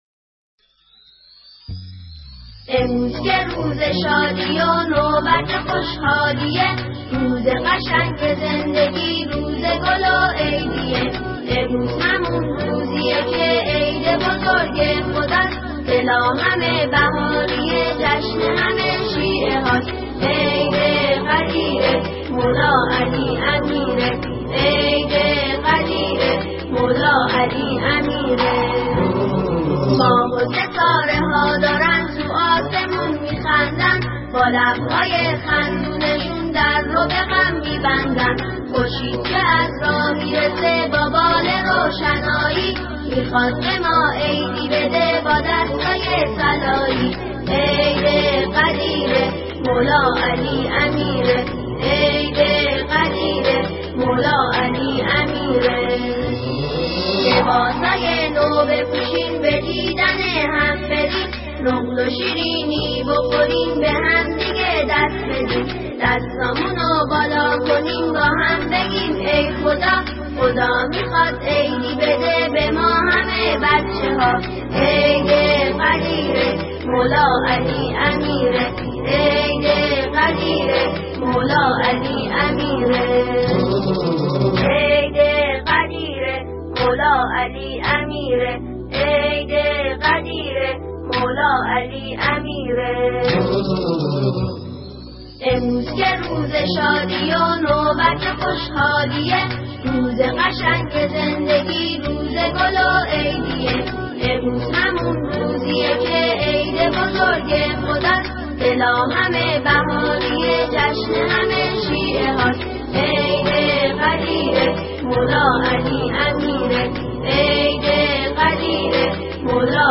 سرودهای عید غدیر خم